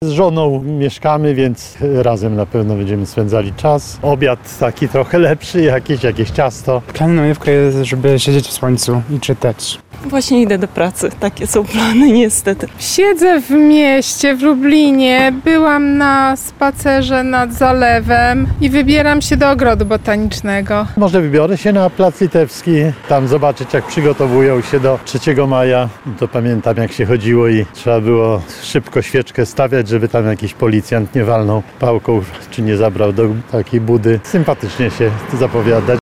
Trwa długi weekend majowy. O plany zapytaliśmy mieszkańców Lublina.